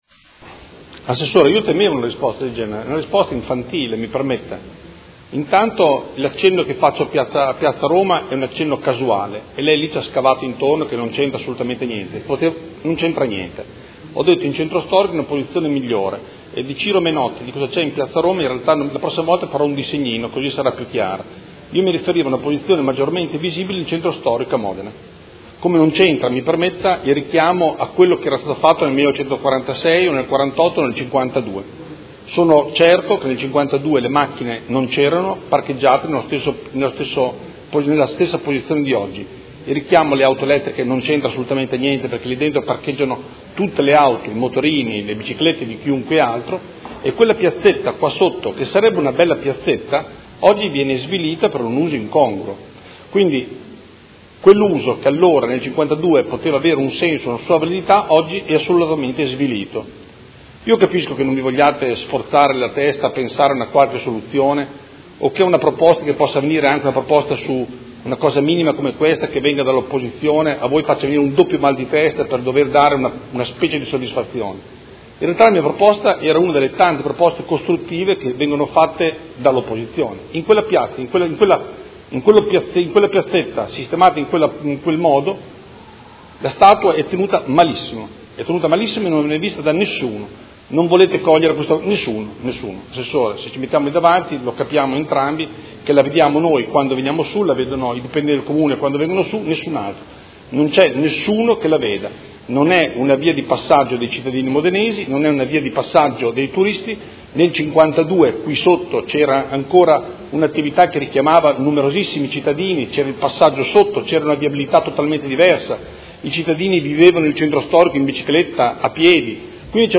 Seduta del 20/04/2016. Conclude interrogazione del Consigliere Galli (F.I.) avente per oggetto: Statua del Perseo nel cortile del palazzo Comunale; visto che il cortile viene usato come parcheggio non sarebbe meglio identificare una postazione di maggior visibilità e attrattività per i turisti?